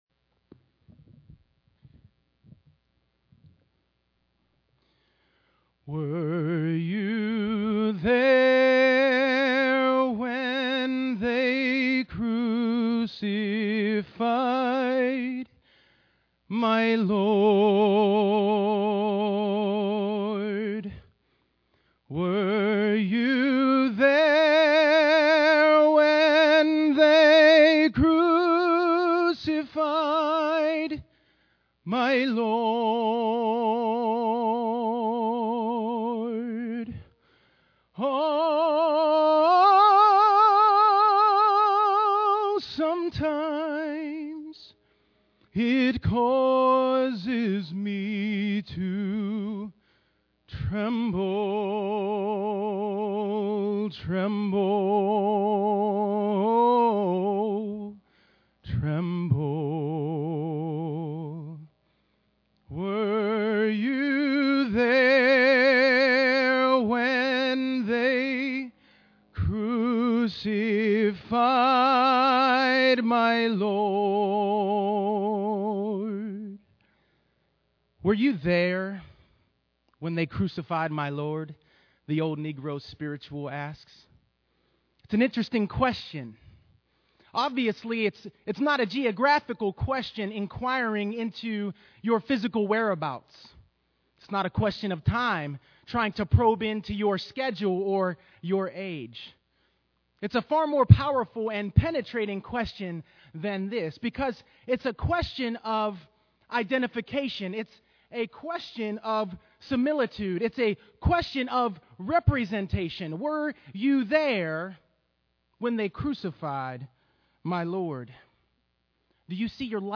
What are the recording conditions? But on Good Friday, we took the time to reflect on the fact that, while we weren’t there physically, our lots are cast with those who drove Christ to the cross. And when Jesus prayed for their forgiveness, he was praying for ours, too.